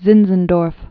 Zin·zen·dorf
(zĭnzən-dôrf, tsĭntsən-), Count Nikolaus Ludwig von 1700-1760.